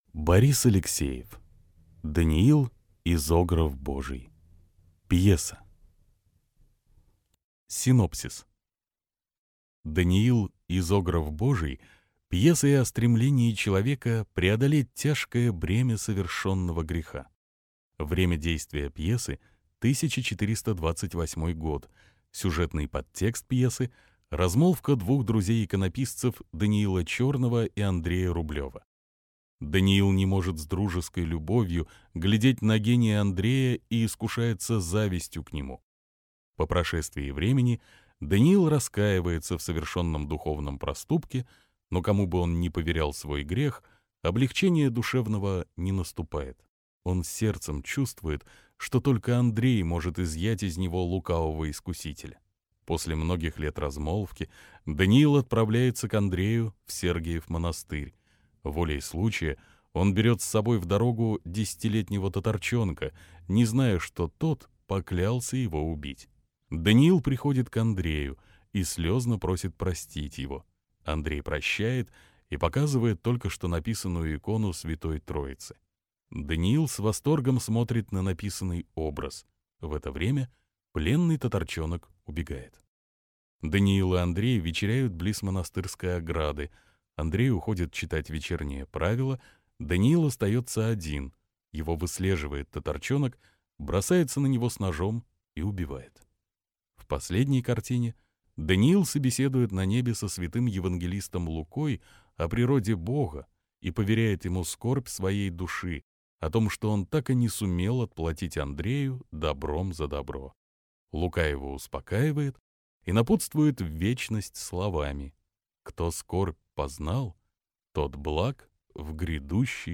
Аудиокнига Даниил, изограф Божий. Пьеса | Библиотека аудиокниг